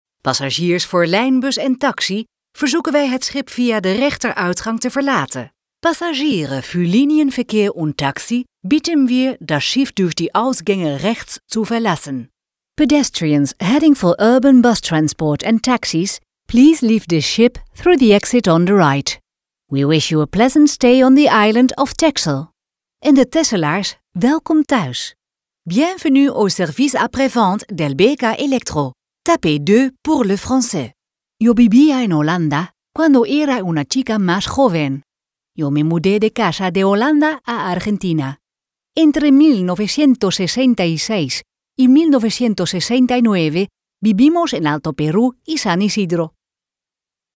Meertalig
Haar communicatie stijl is nuchter, trefzeker, warm en betrokken; to-the-point.
Accentloos, helder en met de juiste toon voor jouw doelgroep.